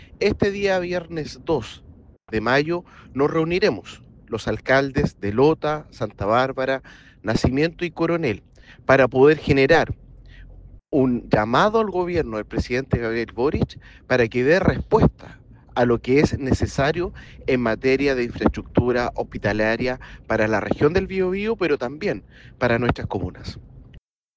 En tanto, el alcalde de Coronel, Boris Chamorro, señaló que espera una pronta respuesta, en Coronel y Lota, agregando que son más de 500 mil las personas que necesitan una mejor infraestructura de salud pública.